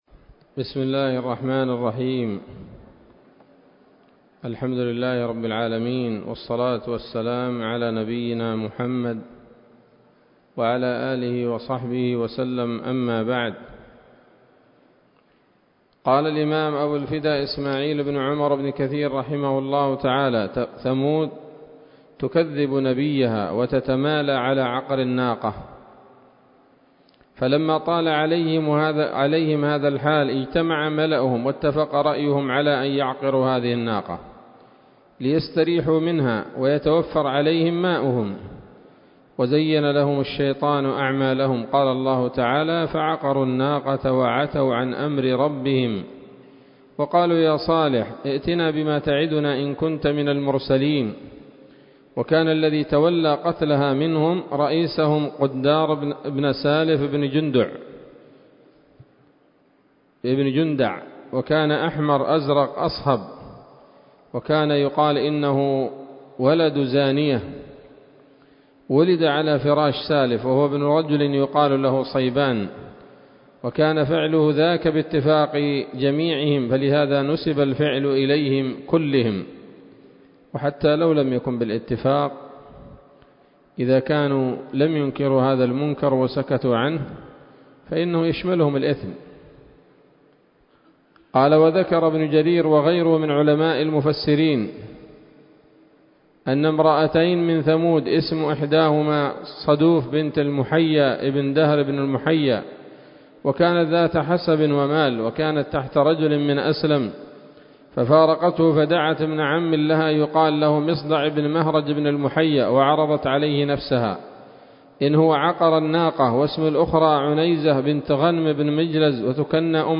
الدرس السادس والثلاثون من قصص الأنبياء لابن كثير رحمه الله تعالى